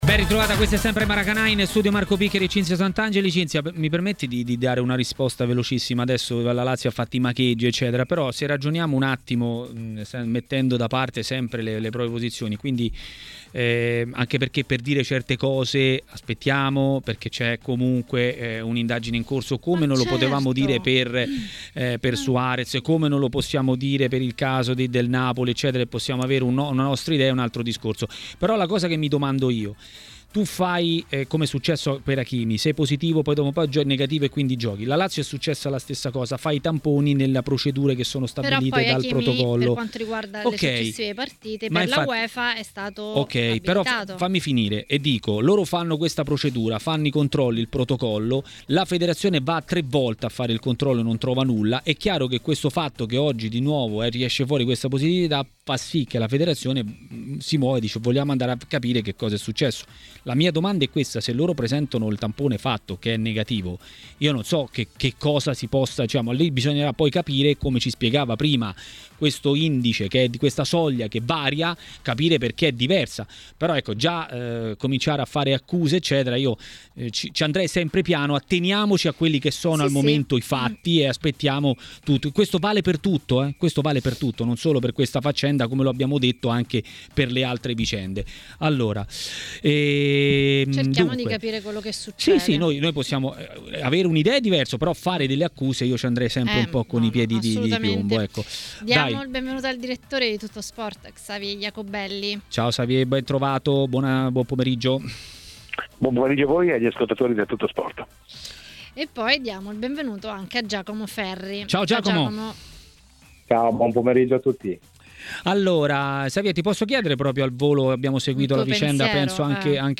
A parlare delle due squadre torinesi a TMW Radio, durante Maracanà